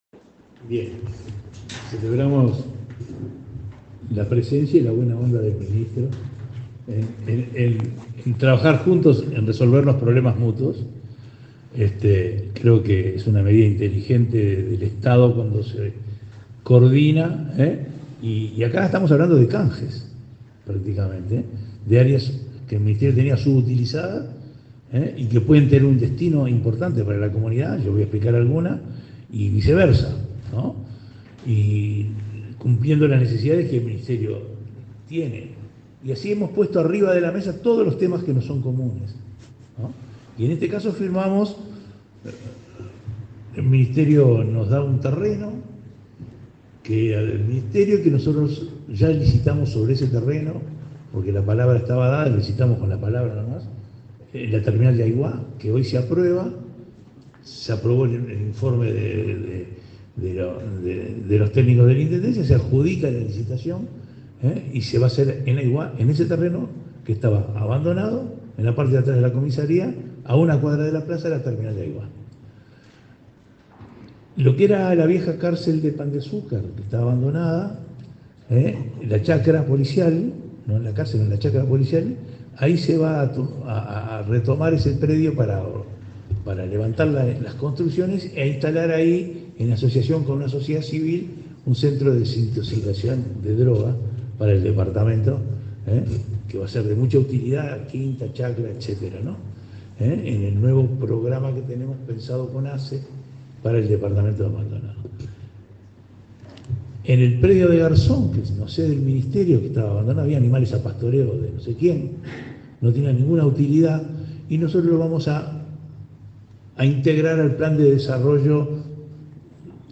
Palabras del intendente de Maldonado y el ministro del Interior
Este viernes 15, en Maldonado, el intendente Enrique Antía y el ministro del Interior, Luis Alberto Heber, firmaron un convenio para utilizar predios